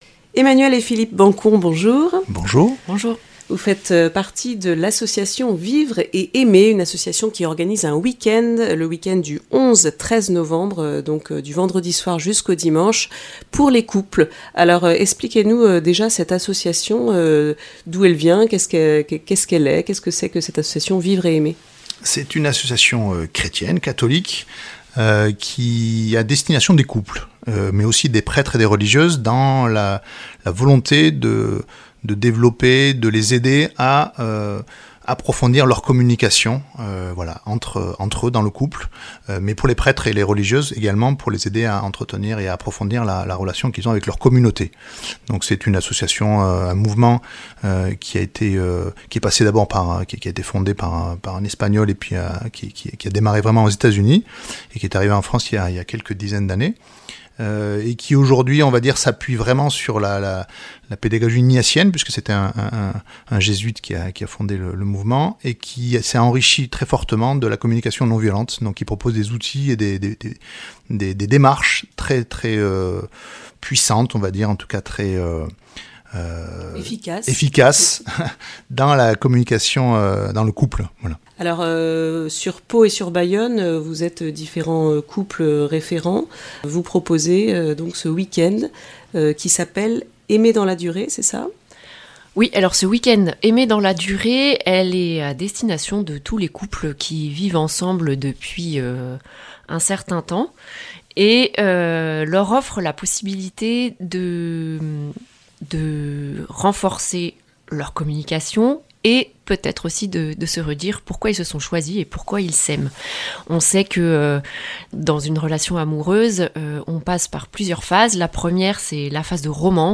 Interviews et reportages